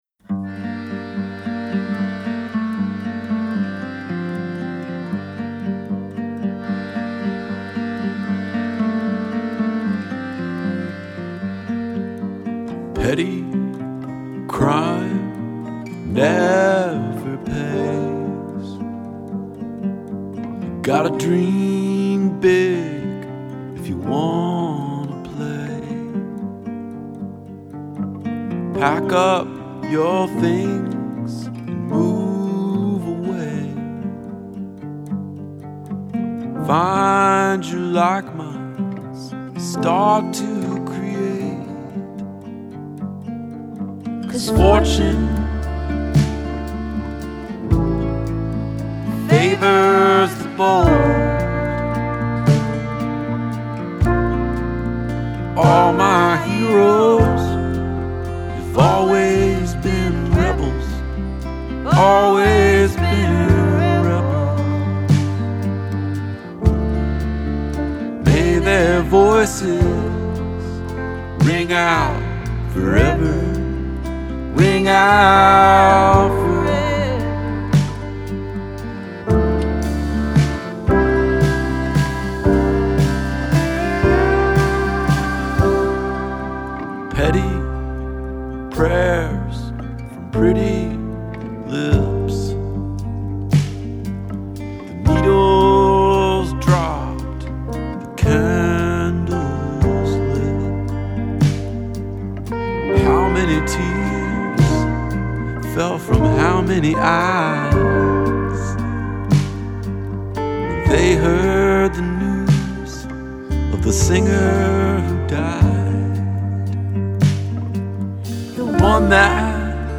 Piano
Accordion
Bass
Drums